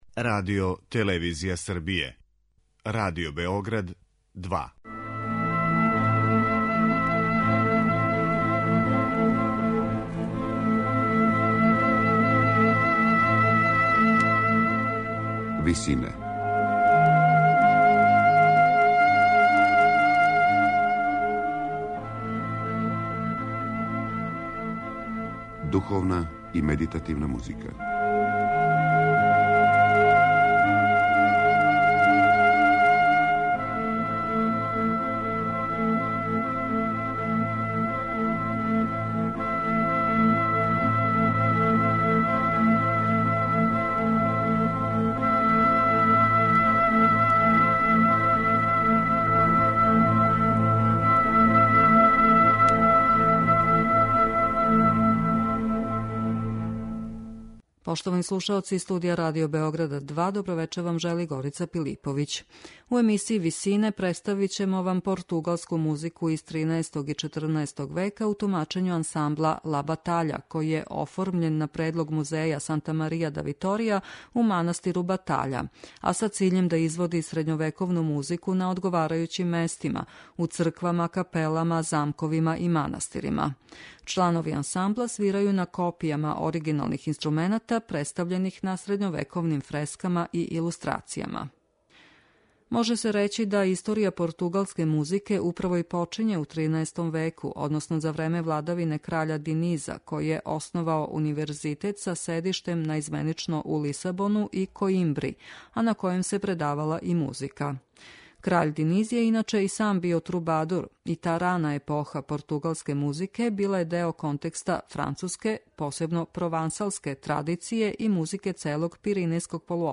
Можете слушати средњовековни репертоар португалског ансамбла 'Ла батаља'